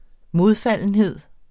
Udtale [ ˈmoðˌfalˀənˌheðˀ ]